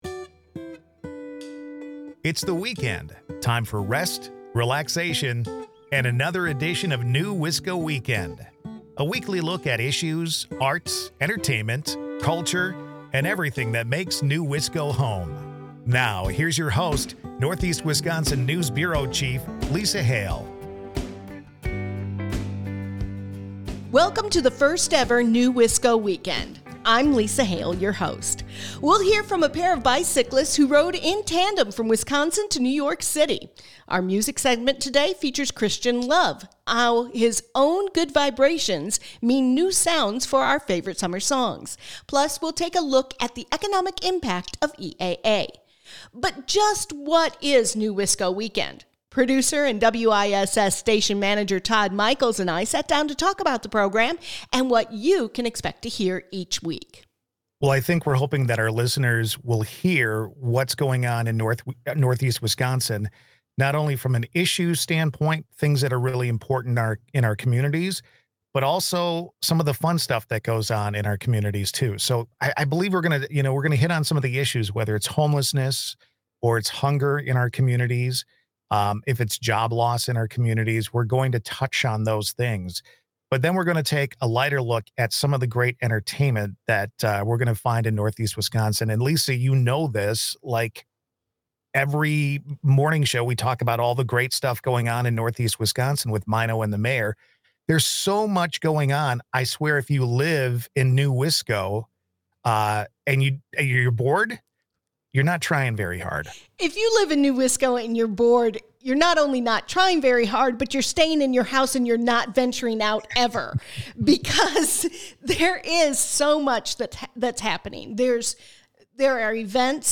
his week on the inaugural episode of NEWisco Weekend, a conversation with a couple who biked from Green Bay to New York City. EAA is just around the corner.